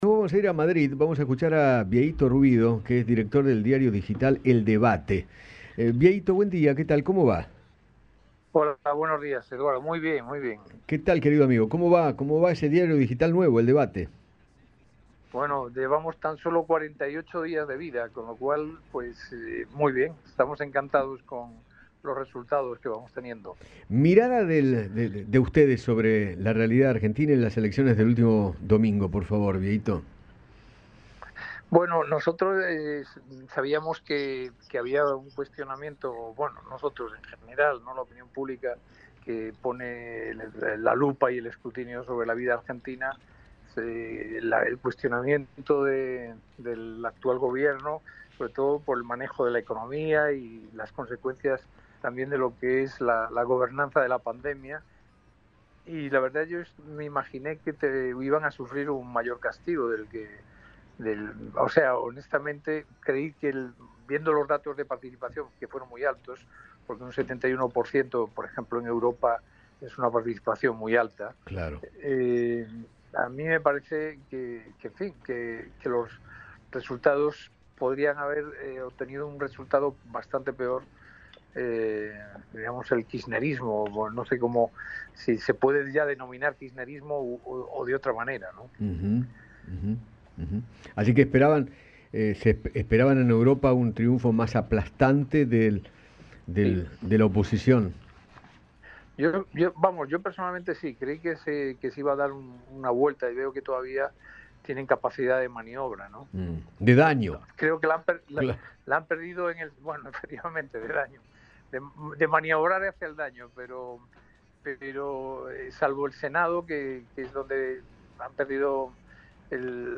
conversó con Eduardo Feinmann  sobre el resultado de las elecciones legislativas, en las que el Frente de Todos fue derrotado en 15 provincias y perdió el control del Senado.